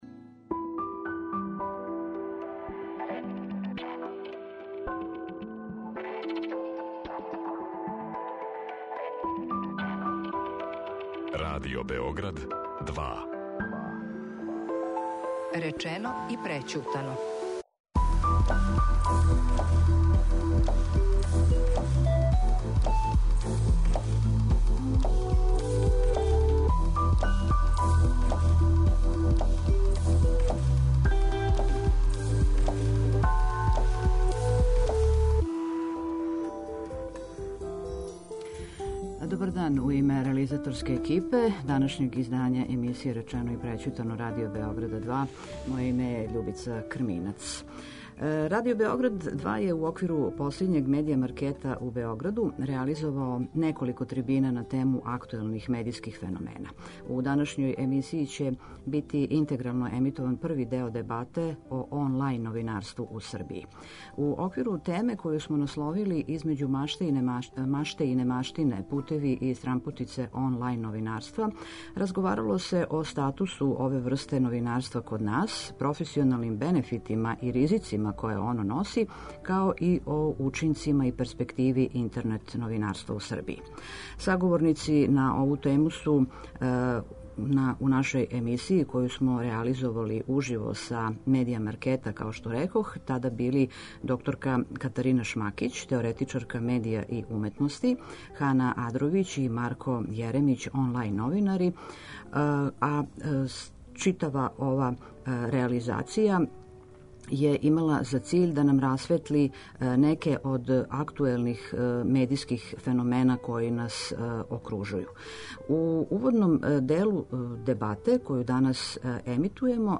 Радио Београд 2 је у оквиру последњег Медија маркета у Београду реализовао неколико трибина на тему актуелних медијских феномена.